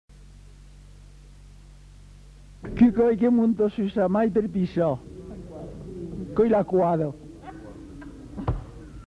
Aire culturelle : Haut-Agenais
Effectif : 1
Type de voix : voix de femme
Production du son : récité
Classification : devinette-énigme